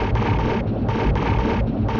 Index of /musicradar/rhythmic-inspiration-samples/120bpm